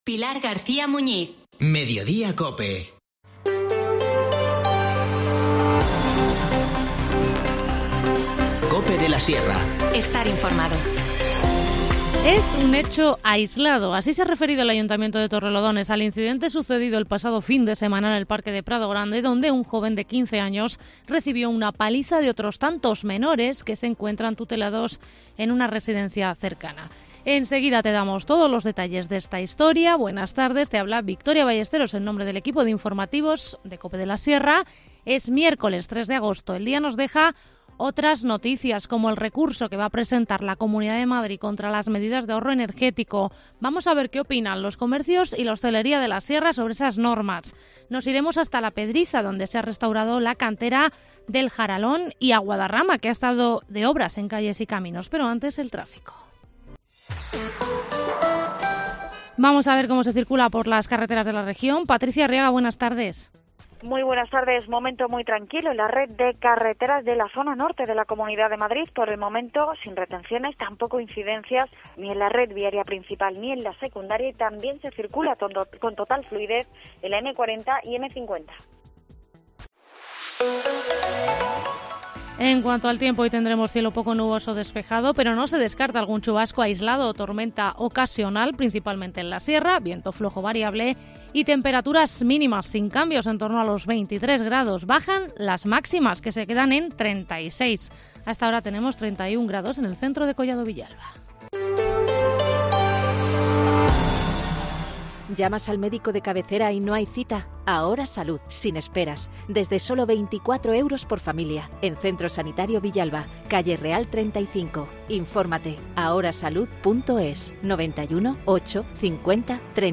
Informativo Mediodía 3 agosto